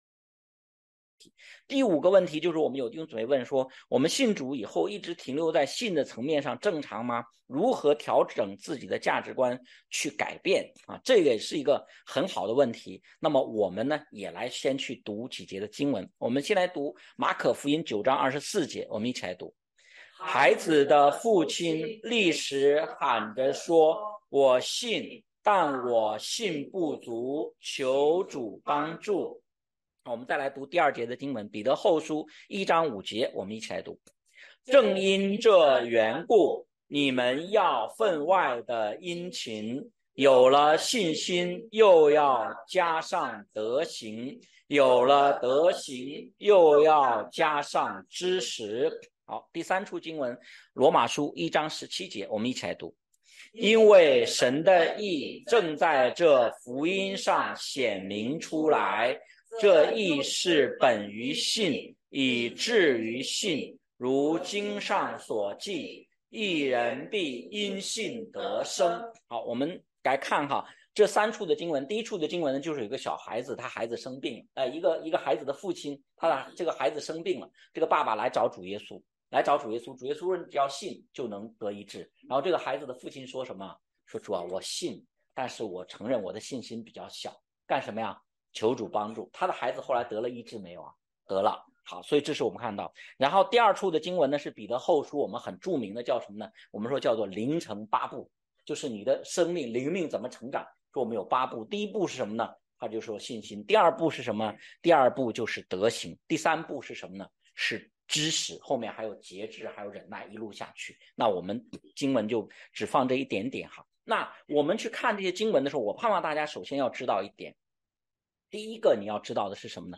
问题解答录音